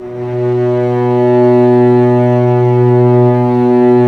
Index of /90_sSampleCDs/Roland - String Master Series/STR_Vcs Bow FX/STR_Vcs Sordino